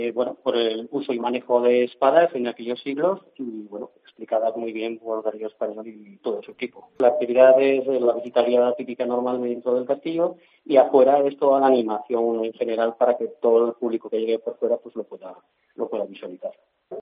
El alcalde de Quicena, Rafael Blasco habla de las jornadas divulgativas del castillo de Monteraragón